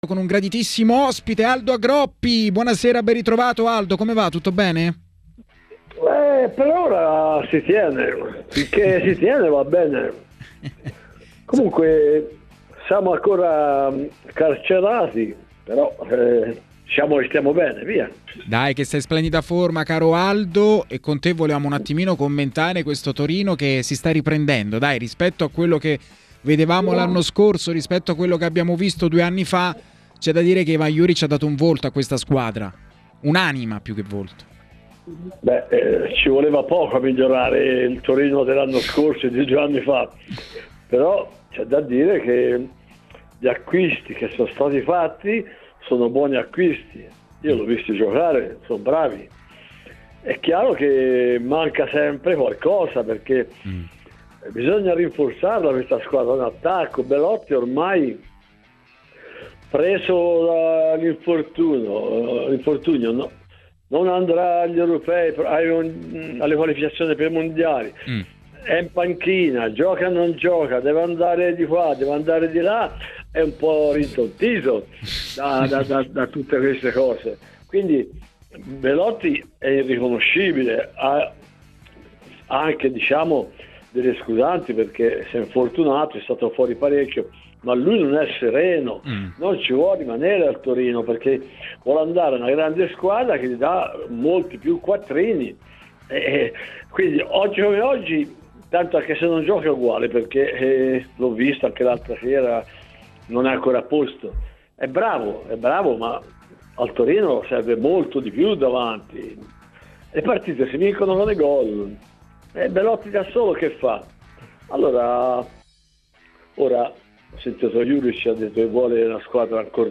Ascolta l'audio Aldo Agroppi parla così a TMW Radio , analizzando il ko subito dalla Juventus contro l’Hellas Verona: “ Una Juventus brutta, l’allenatore non è sereno ed è entrato in contrasto con diversi giocatori.